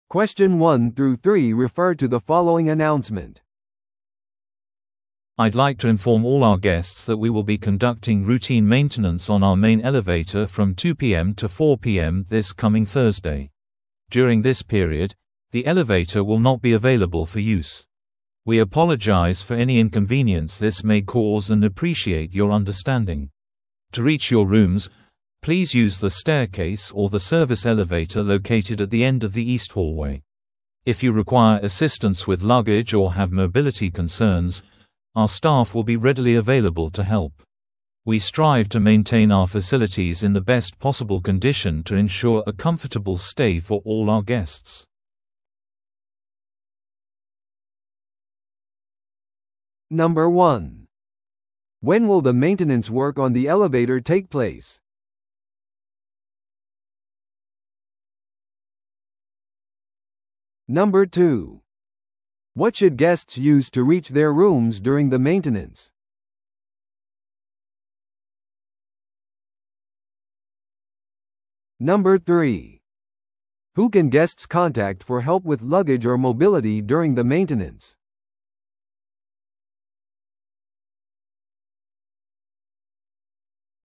TOEICⓇ対策 Part 4｜ホテルのエレベーター工事のお知らせ – 音声付き No.078